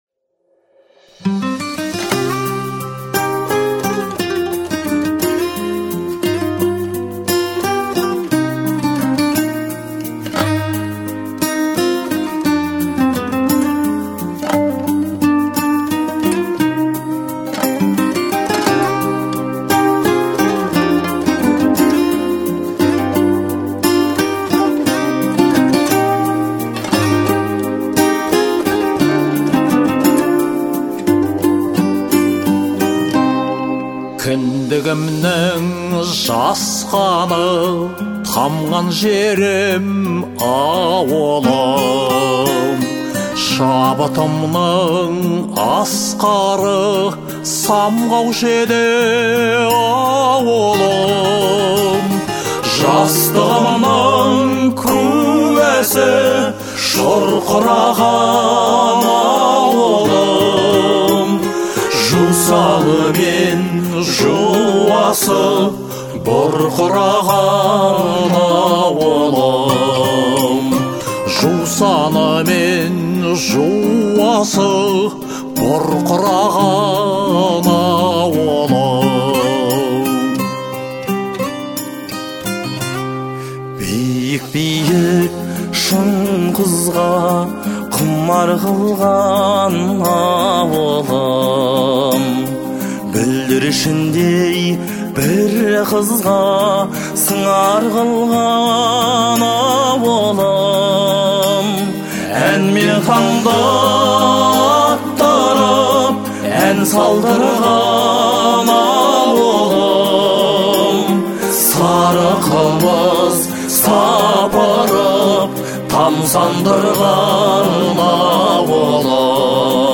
известный своим мощным голосом и эмоциональной подачей